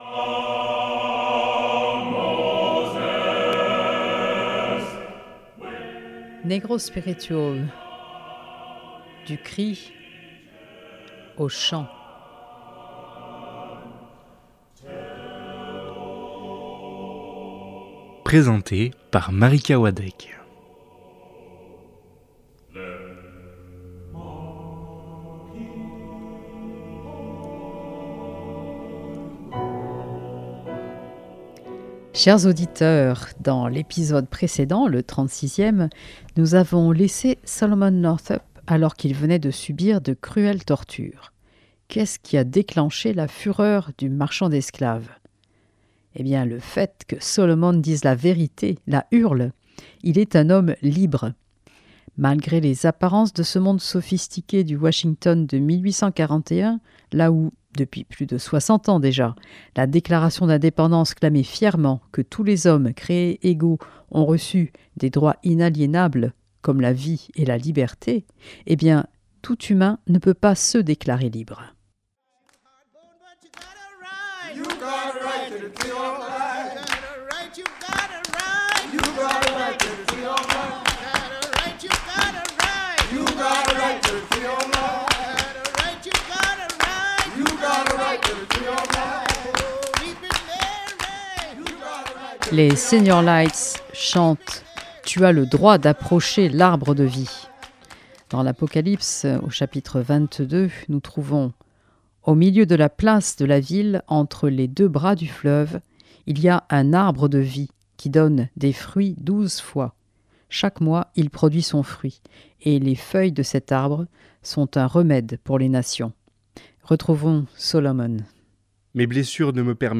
Maintenant, il est en route vers le Sud profond mais, il reste confiant et déterminé : la justice de Dieu finira par éclater ! Pour accompagner son périple, six chants qui expriment ce qu’il ne peut plus dire à haute voix...